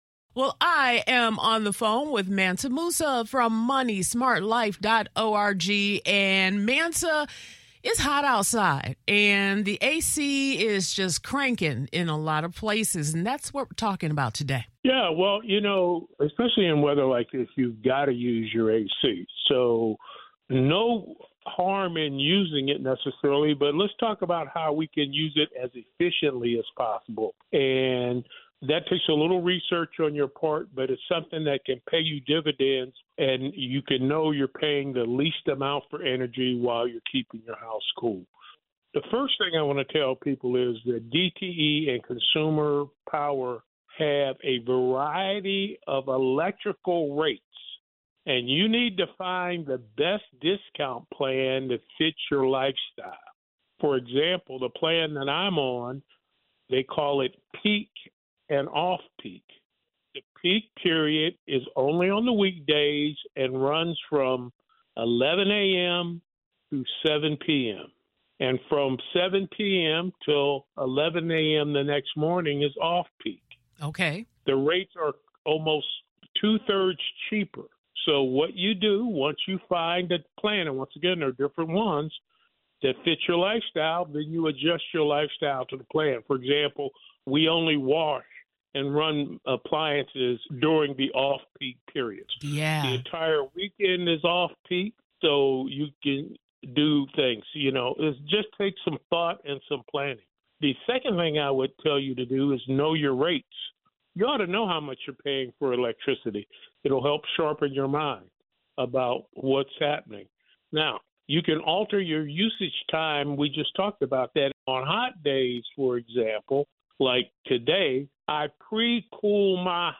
Alternatively, listen on your car, home, or work radio at 105.9 HD2, 98.3 FM, or 99.9 FM.